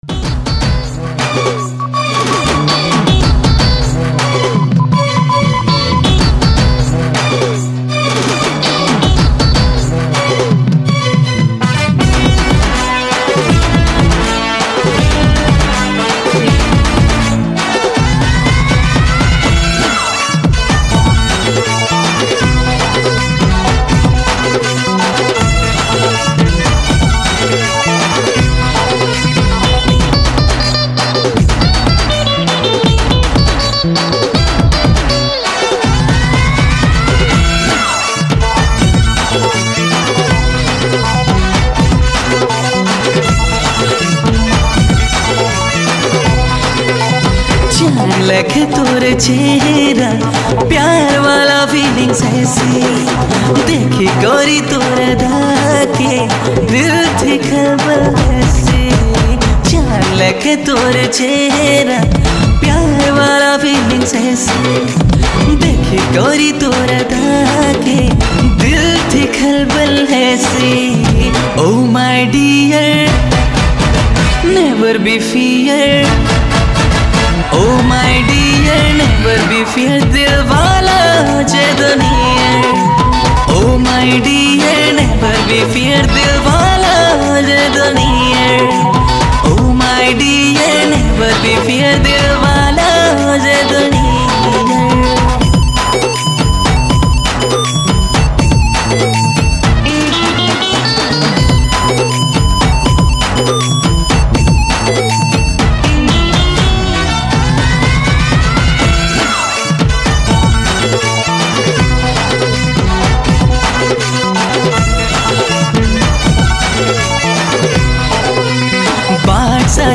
Sambalpuri